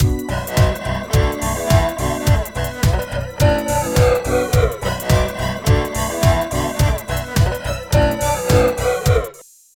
47 LOOP   -L.wav